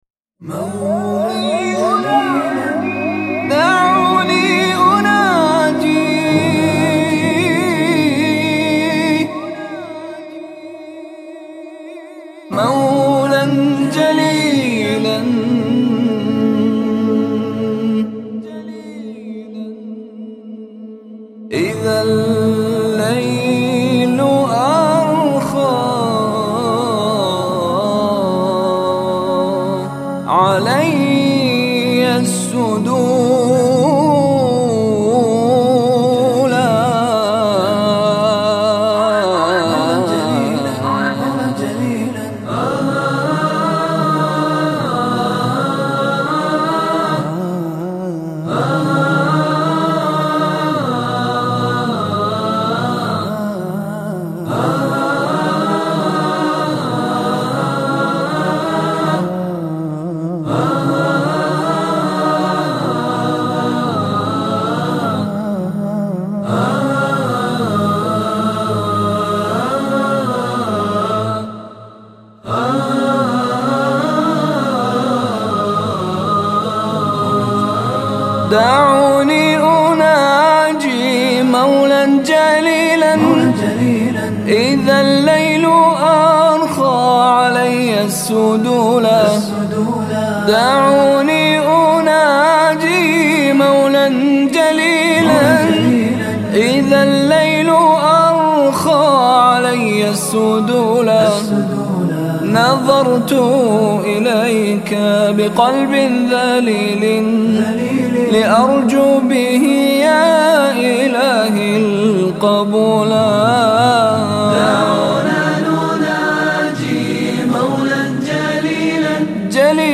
الاناشيد